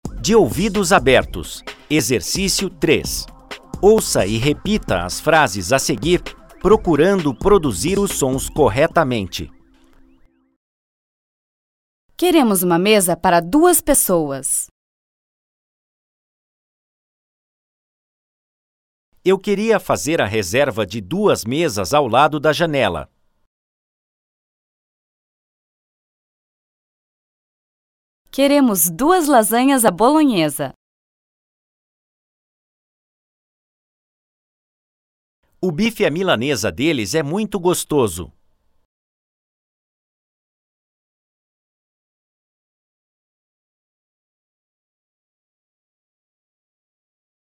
Pronúncia surdo e sonoro frases.mp3